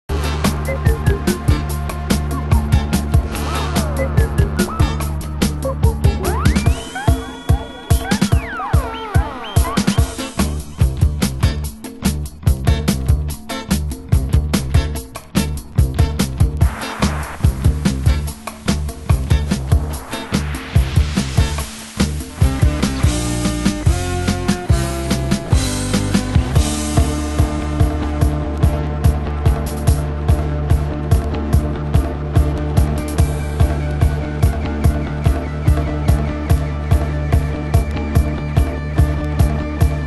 ご機嫌な口笛も◎のCOSIMIC/FUNKブレイク！